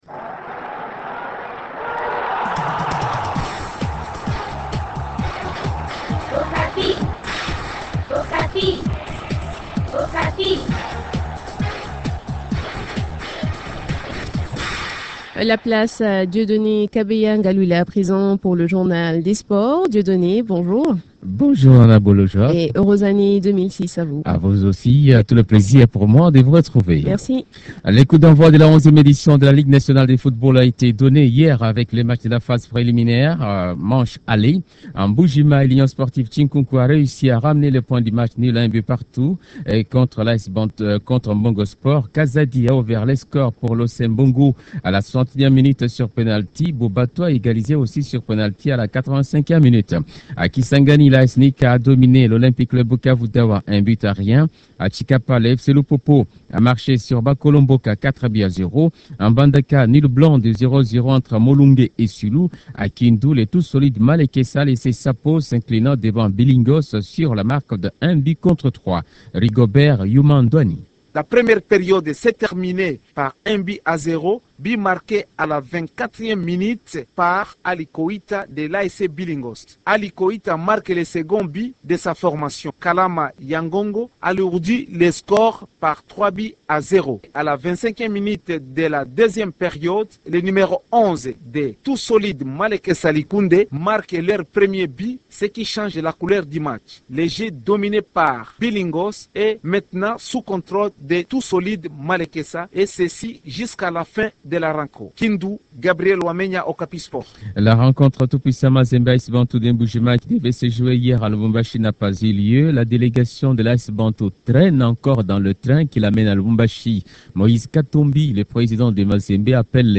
Journal des sports